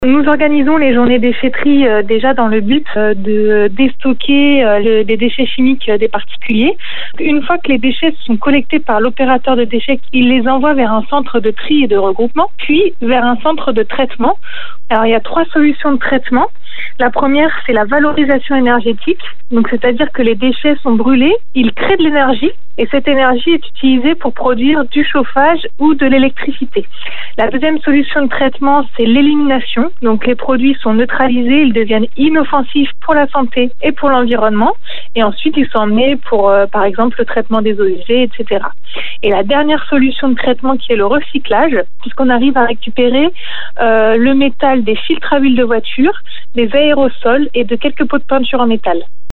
interview_ecodds.mp3